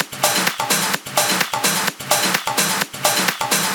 VEH1 Fx Loops 128 BPM
VEH1 FX Loop - 23.wav